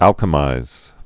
(ălkə-mīz)